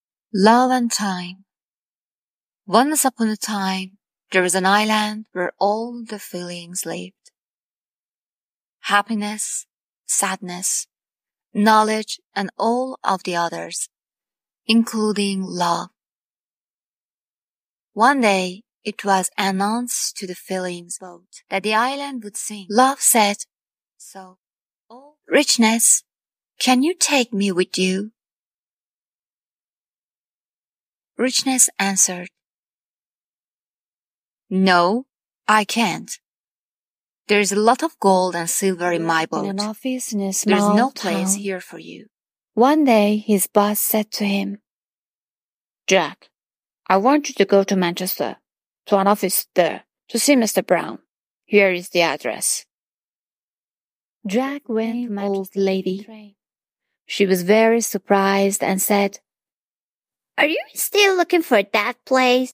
Commercial Demo
I am a Persian voice actress.
Rode procaster microphone
LowVery Low
CalmAttractiveYoungKindGentleModernBrightSoftSmoothSophisticatedSincereSoothingPositiveLovingFriendlyFeminineNaturalClearRelaxedReliableWarmQuiet